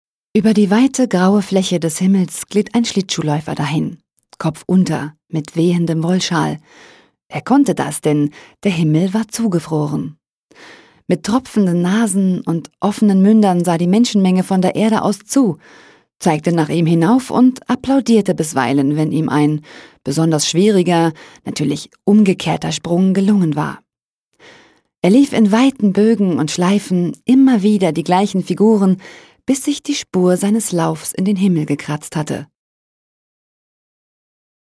Deutsche Sprecherin für Funkspots, TV-Spots, Industriefilm, Warteschleife, Anrufbeantworter. Stimmlage: mittel, weich Stimmalter: 25-40
Sprechprobe: Sonstiges (Muttersprache):